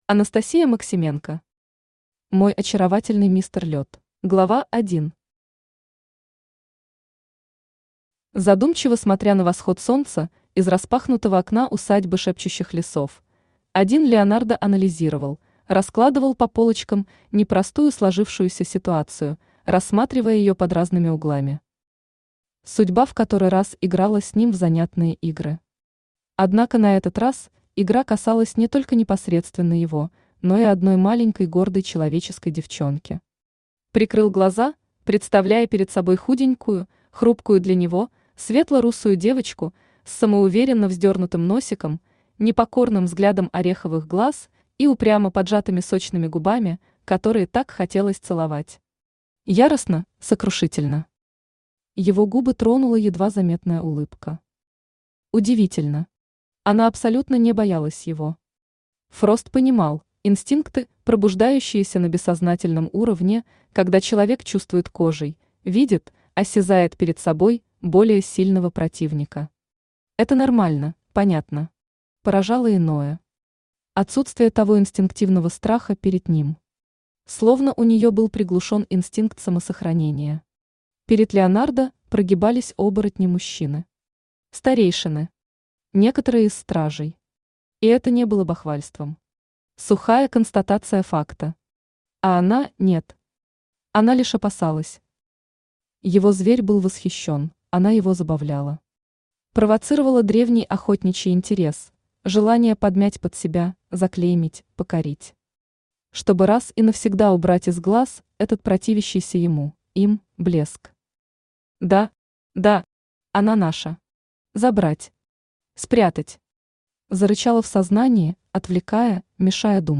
Аудиокнига Мой очаровательный мистер Лёд | Библиотека аудиокниг